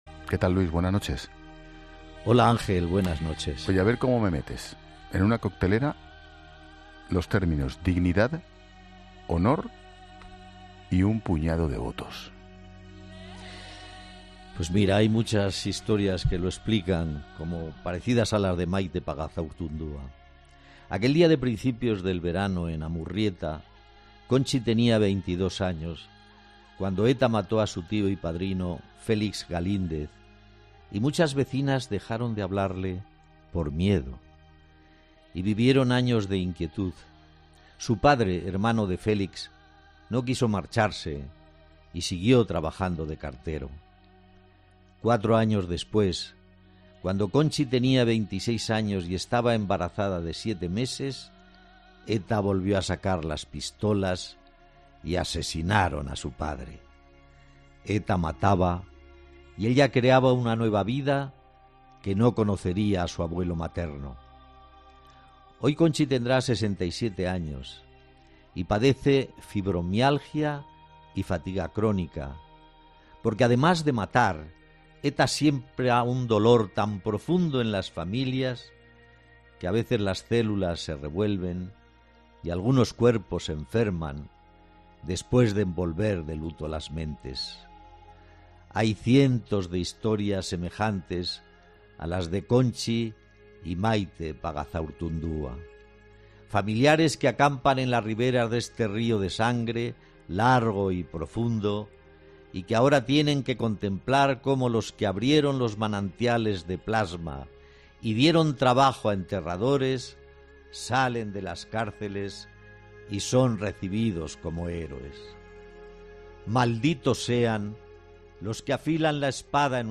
Luis del Val pone el apunte al tema del día de La Linterna con Expósito sobre los beneficios penitenciarios a los presos de ETA